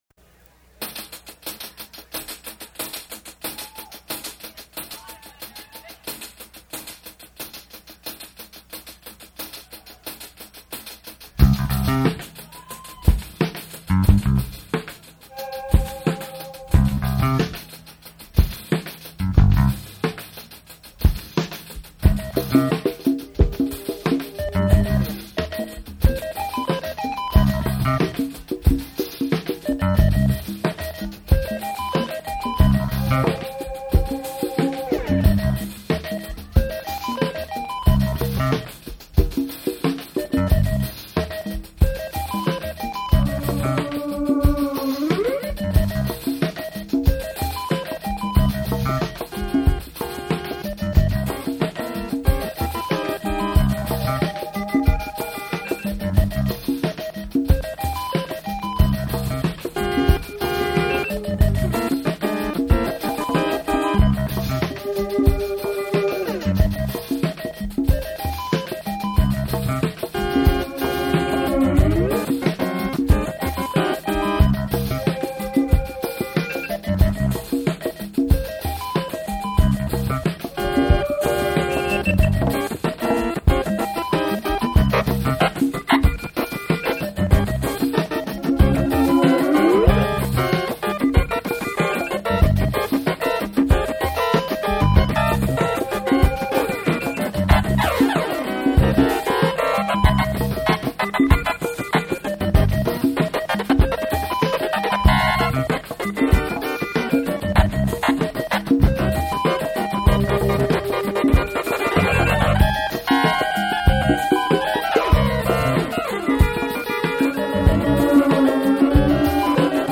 Live at Trax NYC 1981